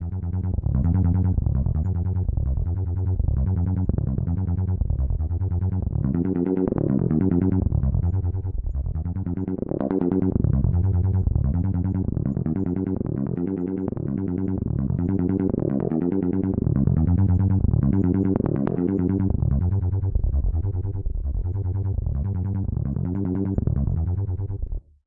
描述：电子振荡器的低音声音在一个永久的重复序列中，由一个中间和非常深的元素建立，通过不断变化的滤波器值来区分。
Tag: 低音 过滤 重复 序列